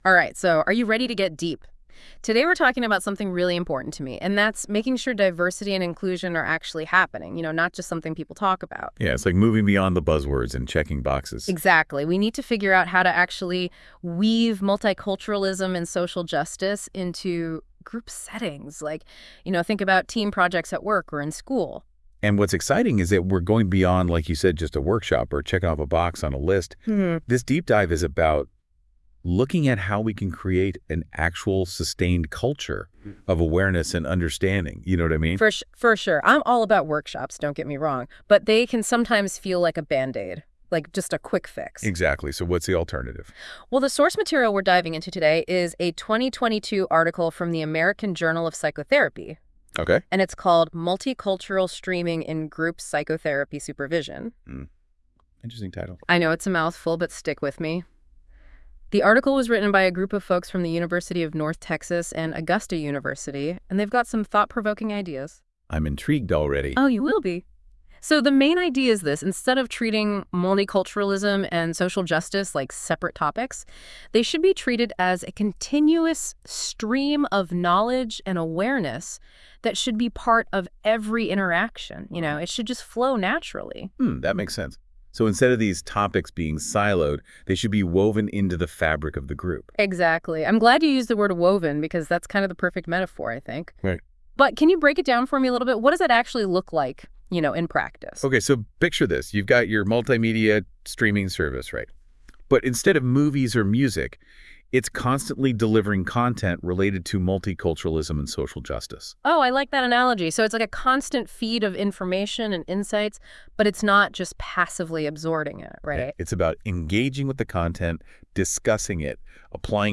This podcast was generated by Notebook LM and reviewed by our team, please listen with discretion.